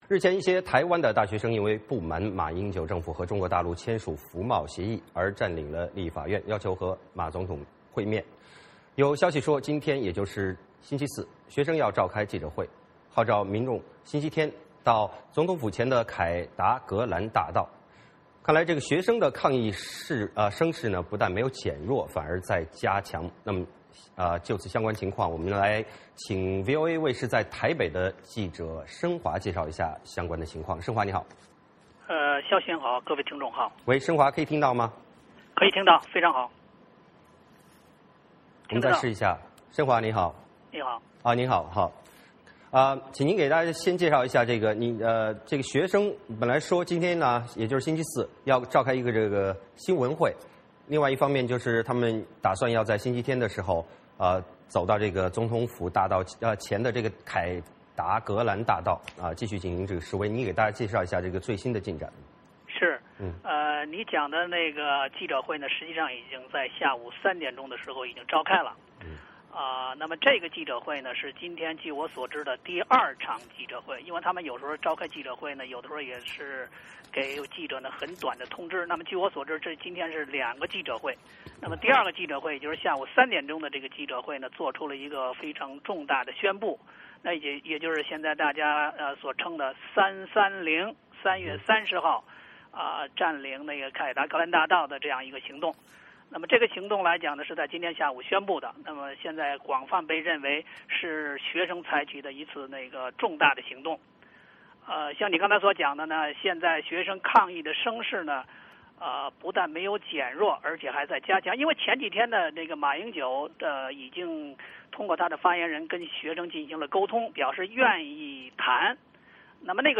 VOA连线：台湾抗议学生号召民众周日举行示威 民意对学生诉求存在分歧